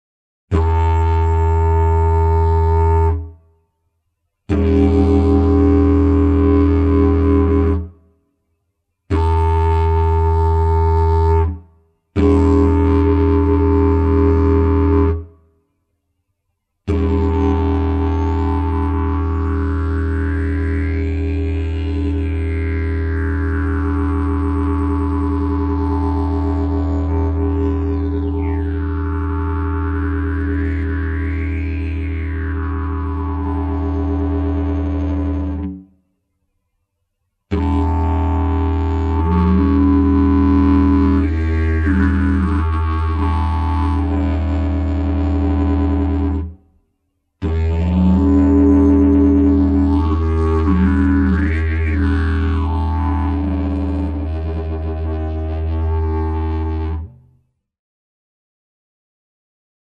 Sample n°8 contiene: differenza del suono con e senza voce, diverse tonalità di voce medio basse e variazioni del suono alterando il volume del cavo orale mantenendo la nota della voce inalterata.